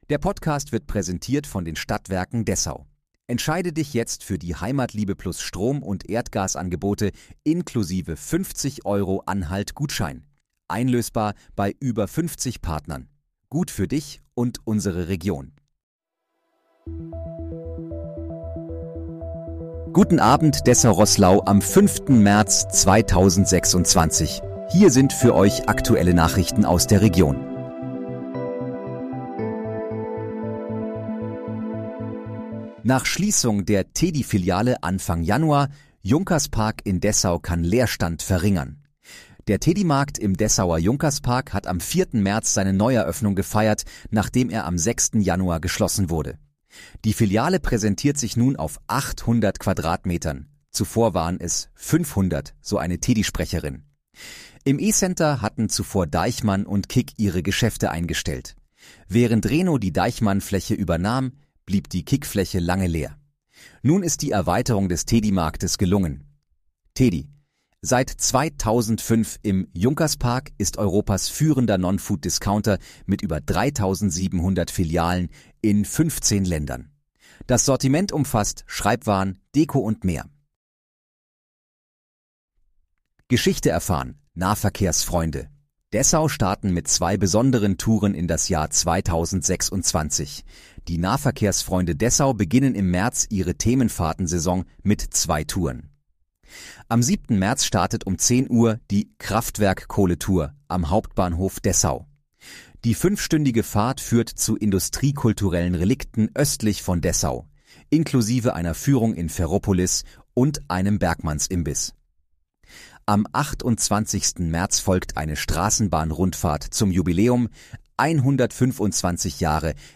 Guten Abend, Dessau-Roßlau: Aktuelle Nachrichten vom 05.03.2026, erstellt mit KI-Unterstützung